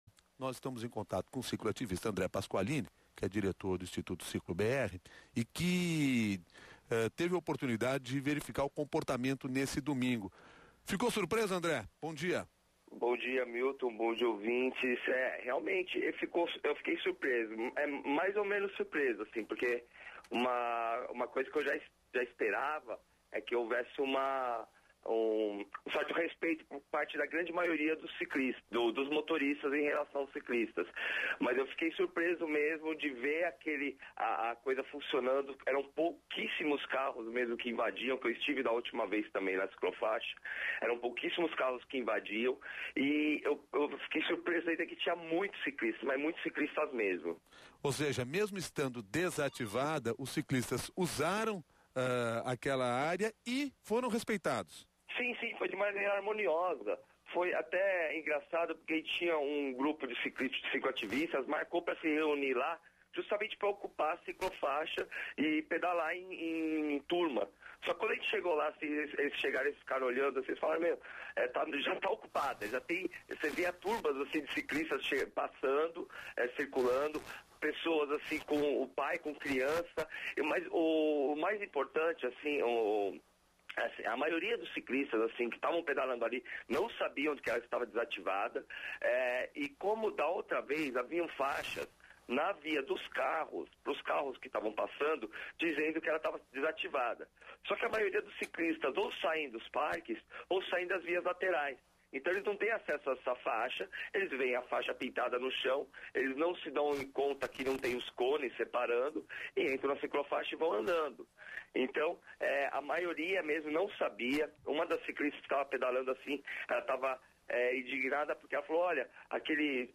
Ouça a entrevista ao CBN São Paulo